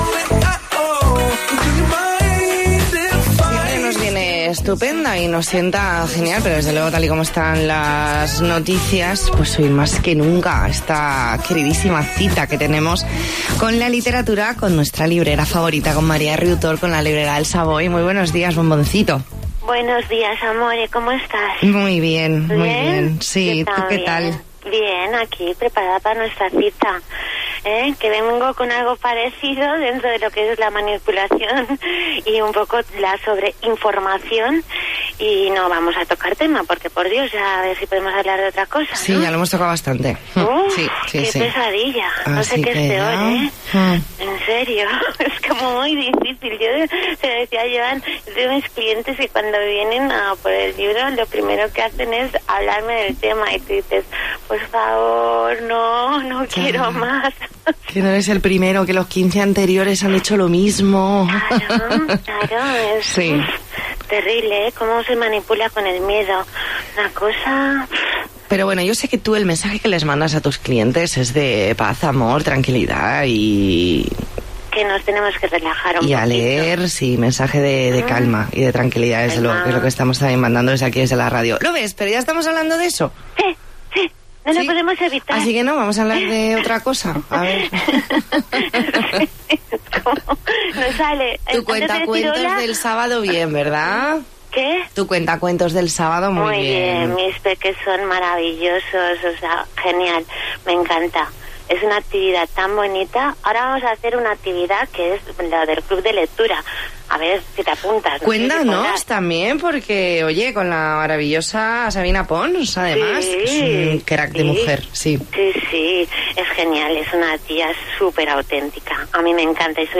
Entrevista en 'La Mañana en COPE Más Mallorca', martes 10 de marzo febrero de 2020.